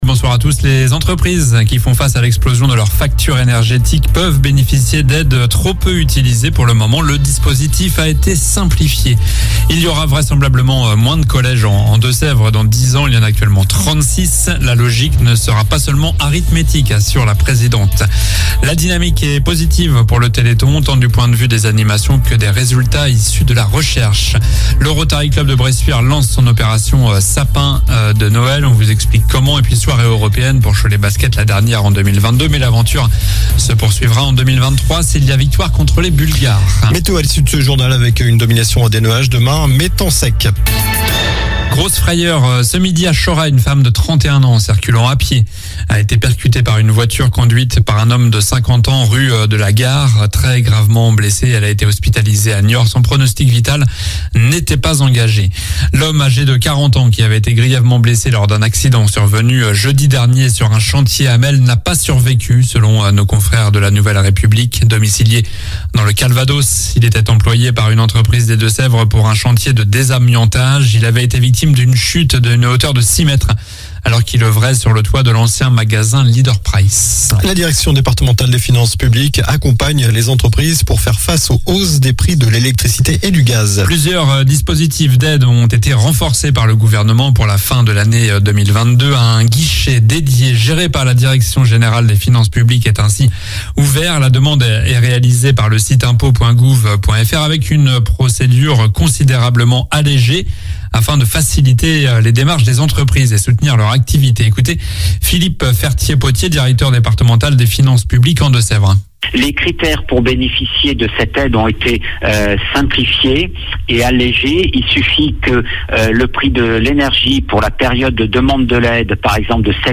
Journal du mardi 29 novembre (soir)